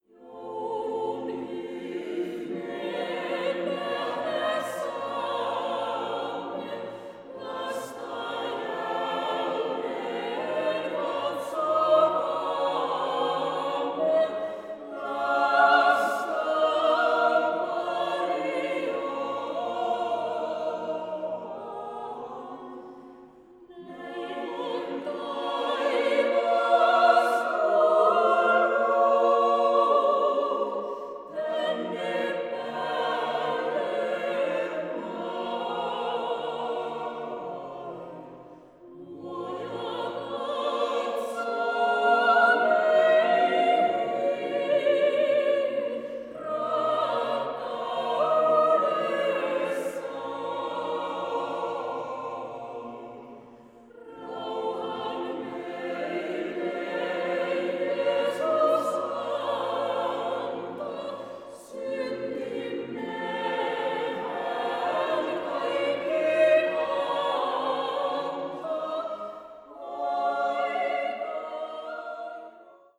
(vahvistettu kvartetti)